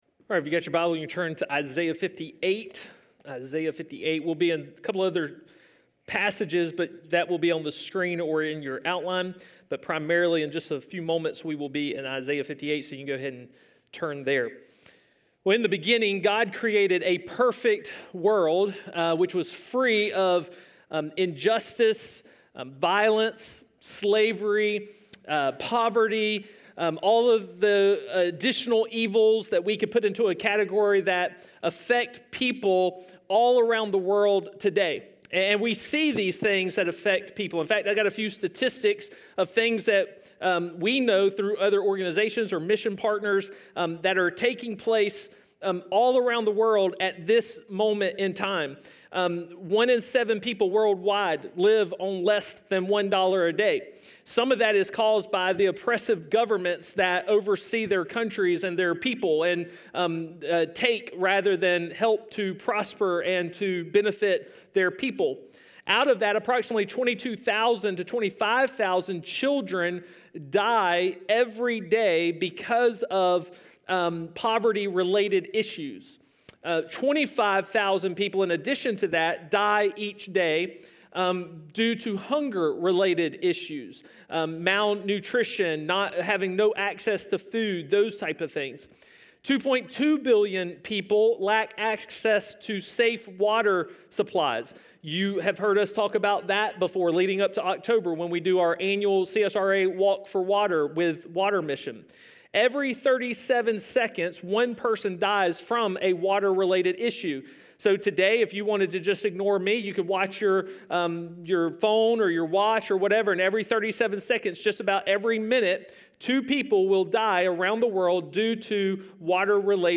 A message from the series "Wake Up!."